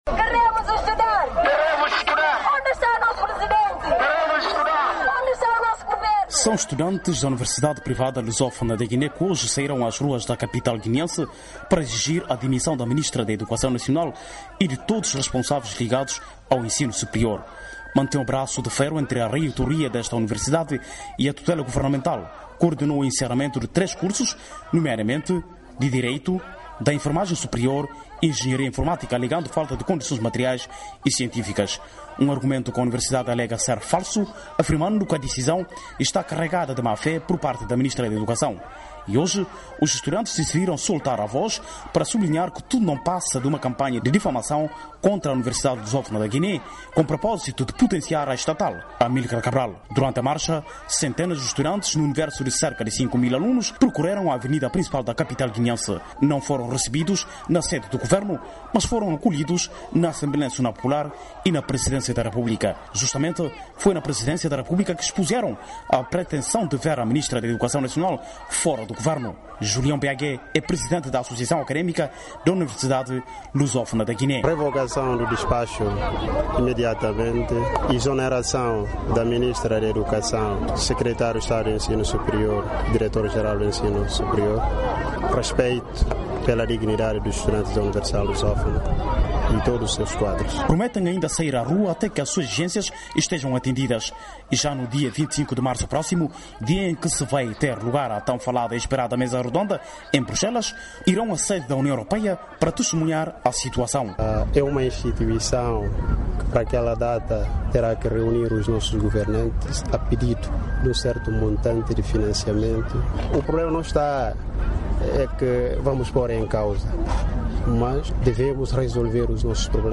Manifestação de estudantes da Universidade Lusófona da Guiné-Bissau